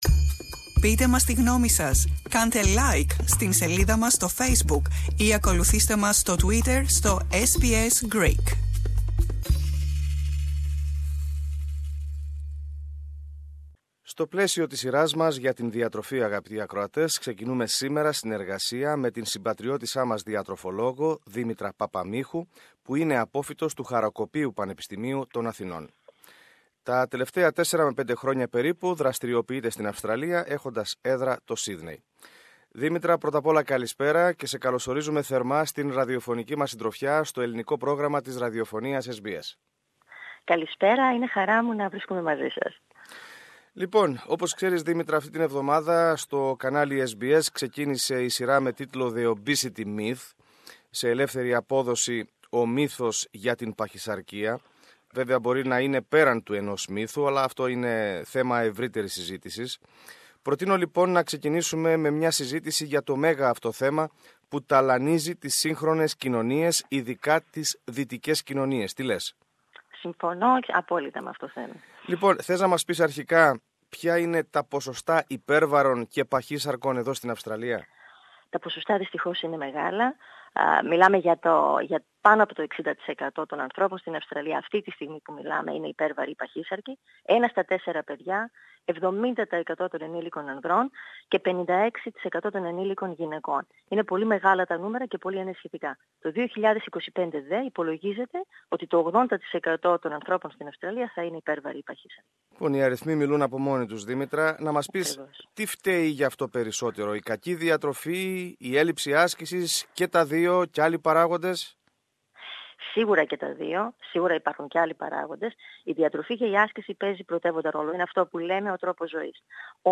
Περισσότερα ακούμε στην συνομιλία